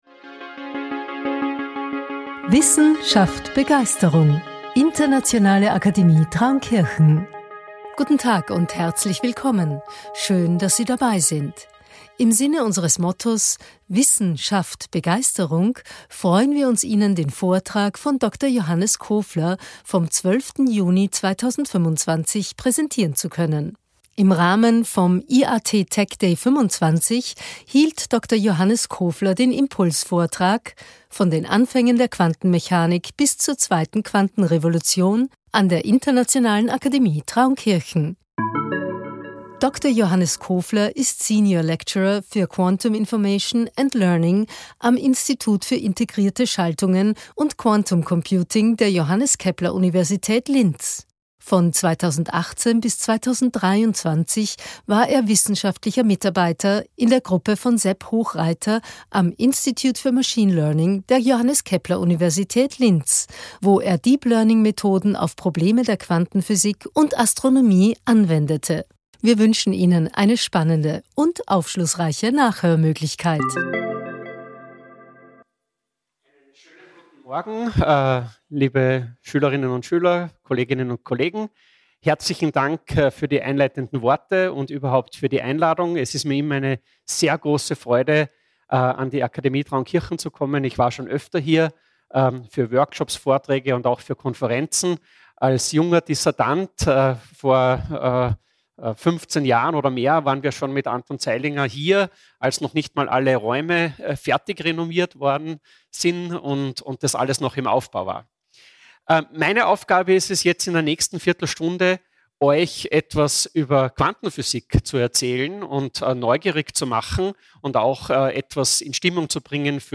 Impulsvortrag